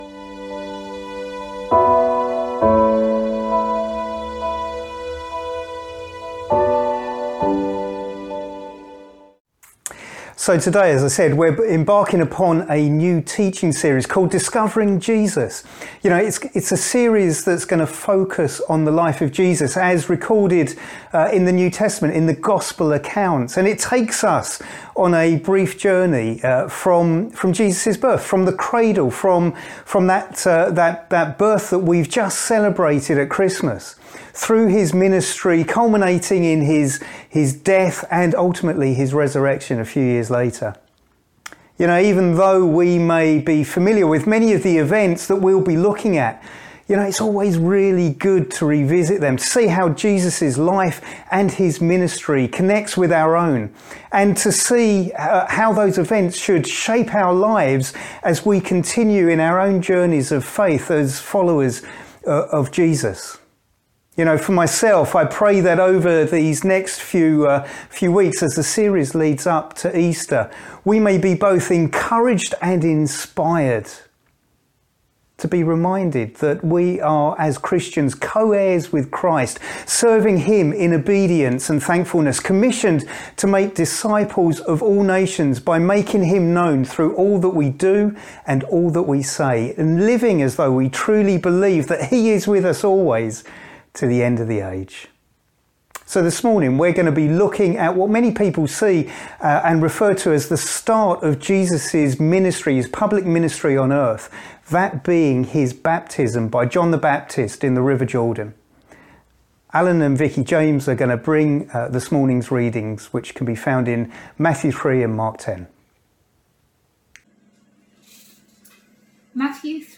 A message from the series "Discovering Jesus."
Sunday Morning - 10:30